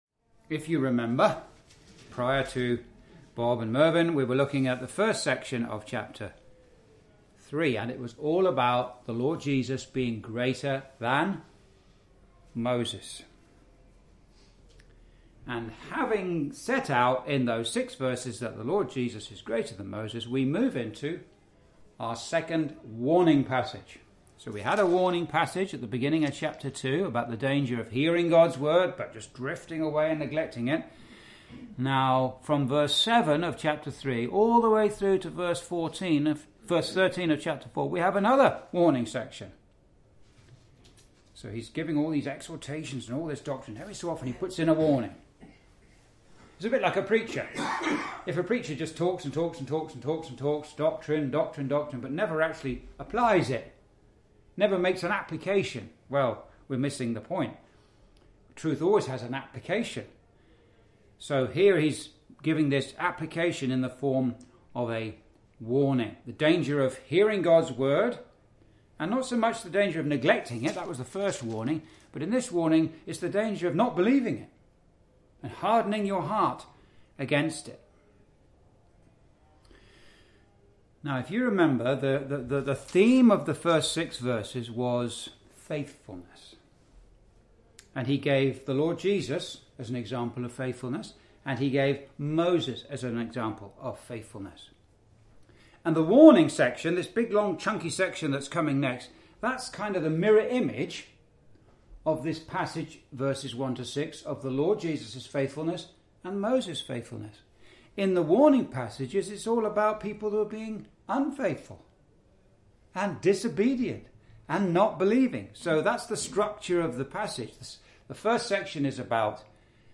(Message preached in Chalfont St Peter Gospel Hall, 2024)
Verse by Verse Exposition